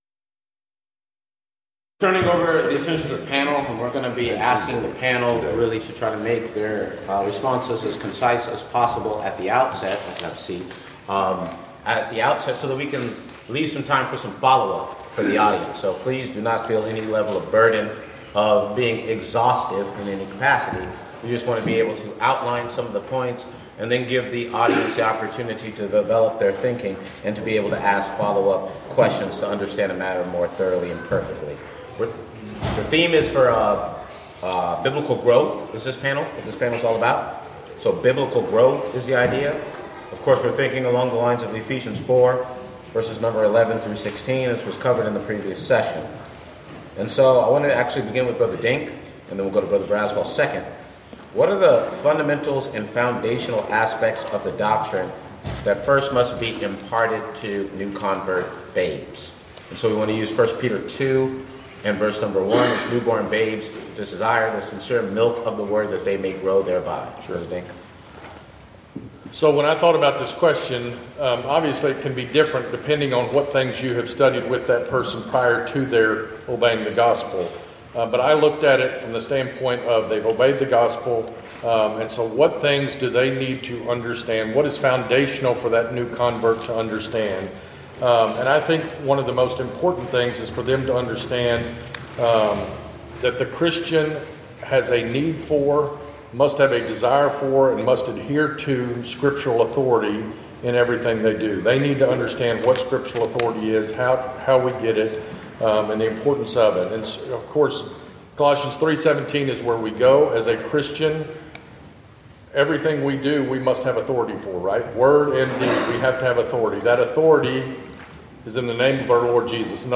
Title: Panel Discussion: Biblical Growth
Event: 5th Annual Men's Development Conference